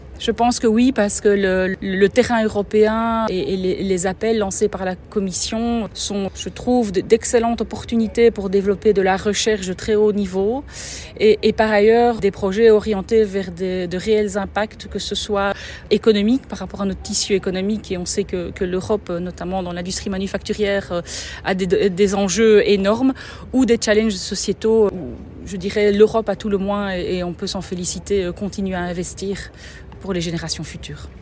Un échange à bâtons rompus et très concret. Un échange teinté d’optimisme.